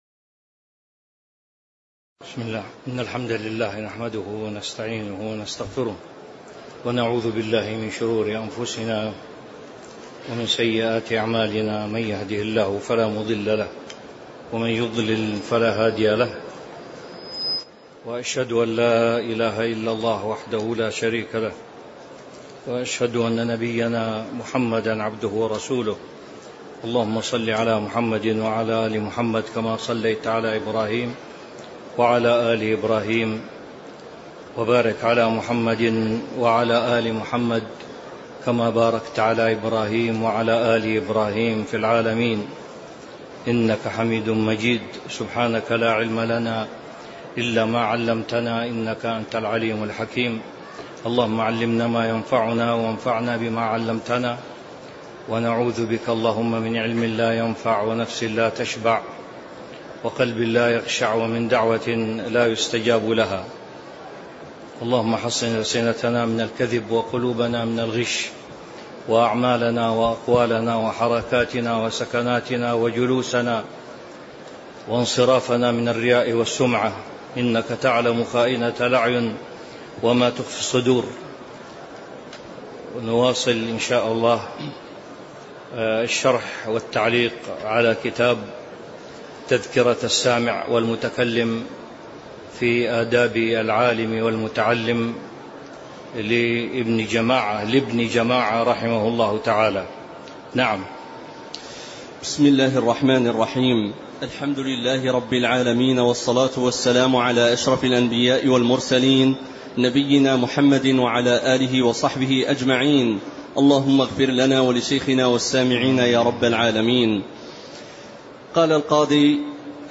تاريخ النشر ٢٣ جمادى الآخرة ١٤٤٦ هـ المكان: المسجد النبوي الشيخ